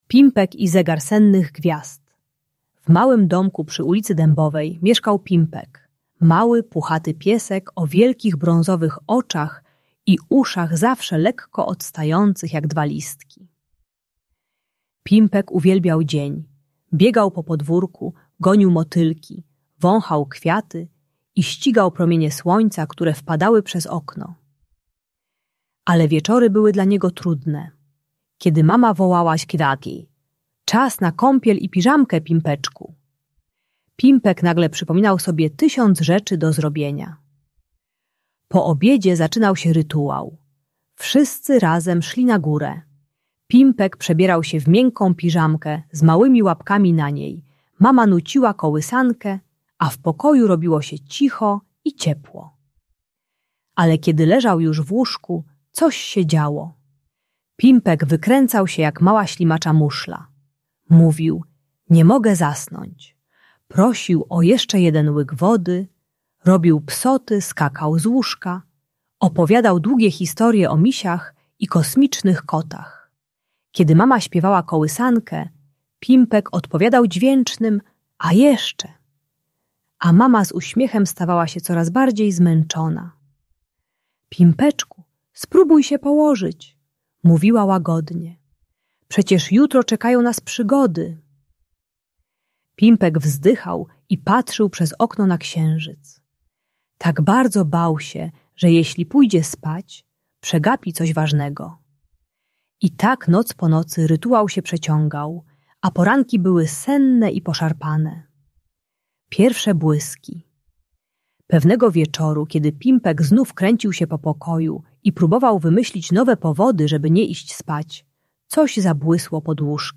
Pimpek i Zegar Sennych Gwiazd - story o magii snu - Usypianie | Audiobajka